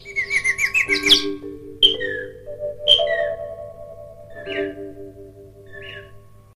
Animals sounds